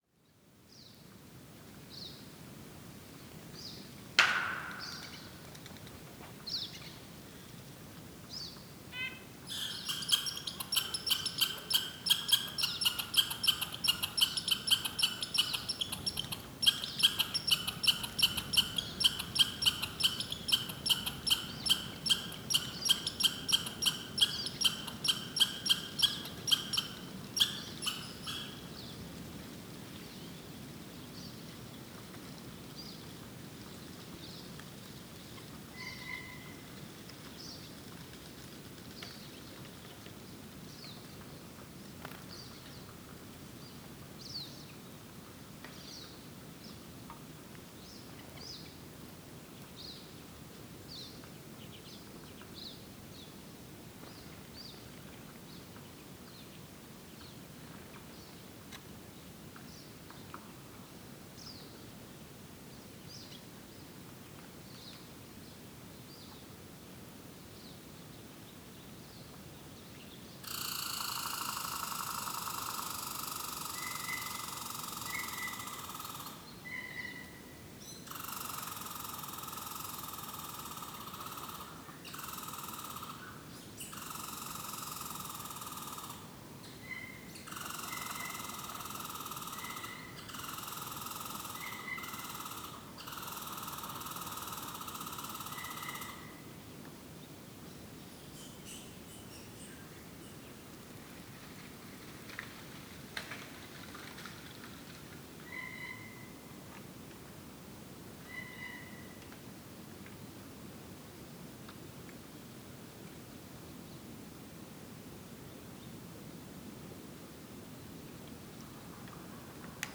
American Red Squirrel – Tamiasciurus hudsonicus
Mont-Tremblant National Park QC, 46°18’48.9″N 74°33’37.9″W. March 21, 2018. 12:30PM.
Species : American Red Squirrel, Pine Siskin, Red-breasted Nuthatch, Blue Jay, Downy Woodpecker.
79726-loiseau-son-ecureuil-roux-parc-national-du-mont-tremblant.m4a